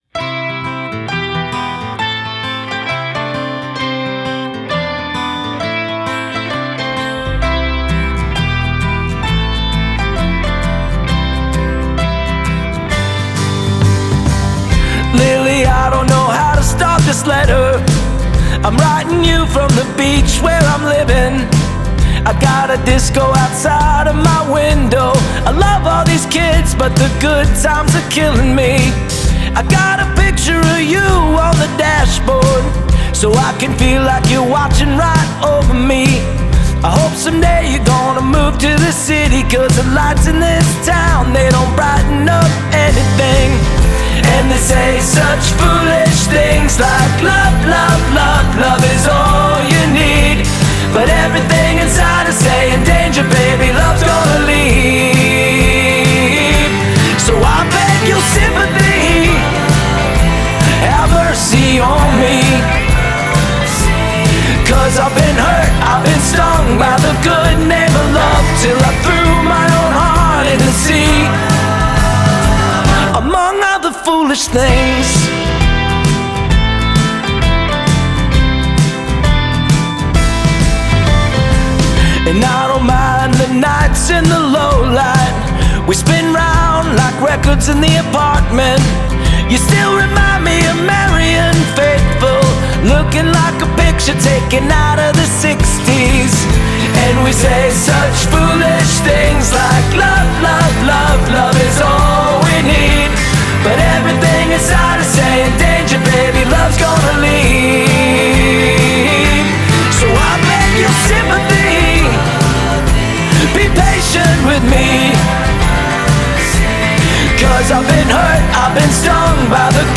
I love the guitar sounds on this record.